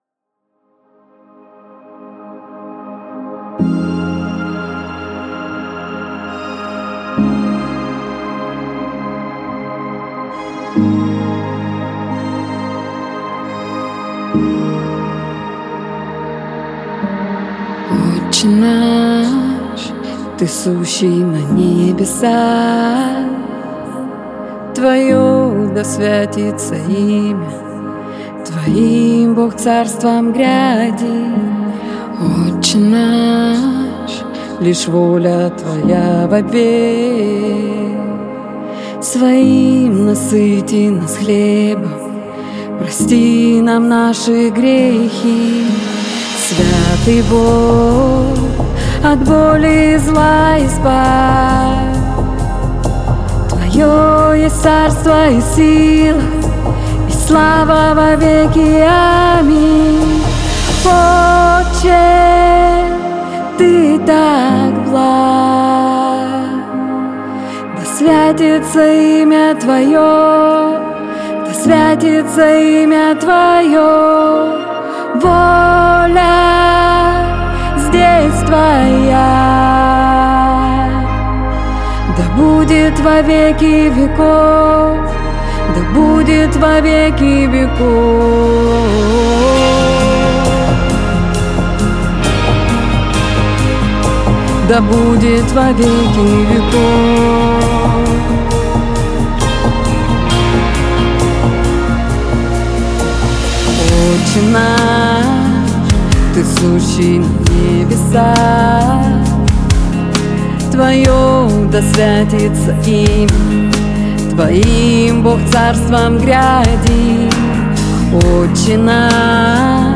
песня
79 просмотров 215 прослушиваний 5 скачиваний BPM: 67